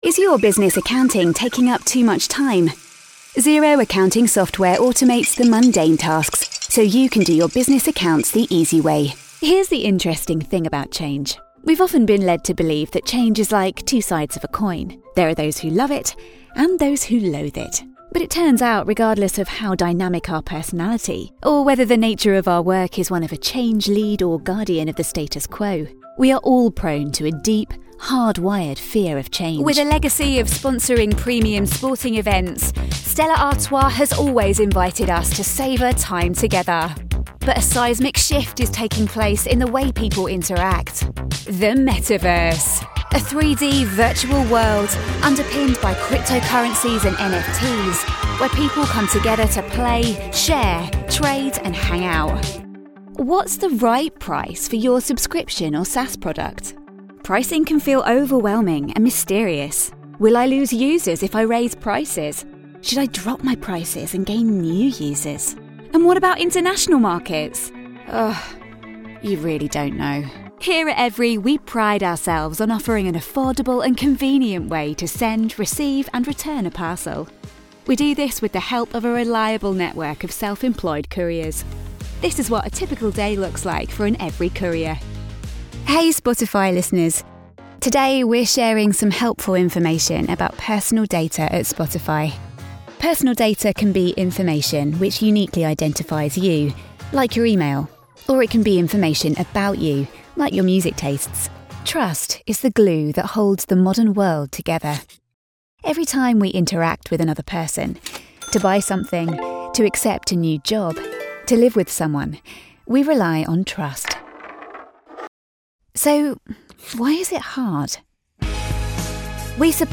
Corporate Showreel
Known for her excellent comic timing, she brings a dynamic edge and is always ready to surprise with a wild card.
Female
Neutral British
Confident
Bright